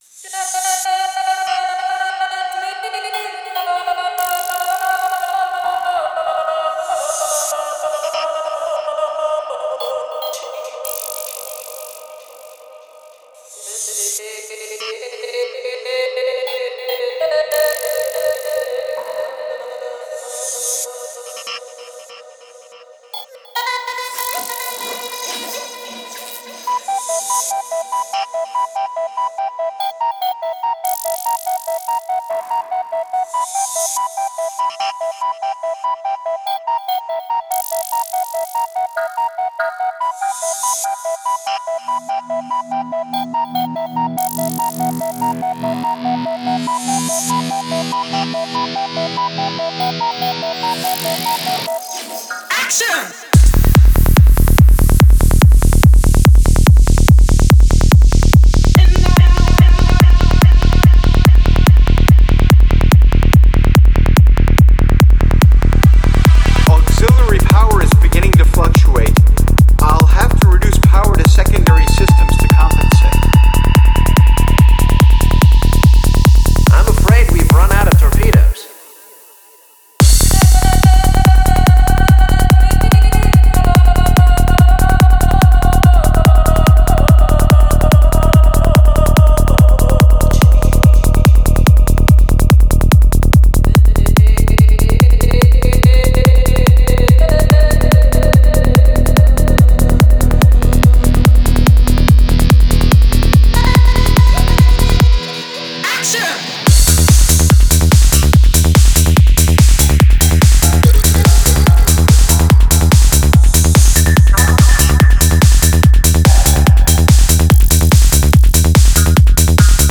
Файл в обменнике2 Myзыкa->Psy-trance, Full-on
Стиль: Psy Trance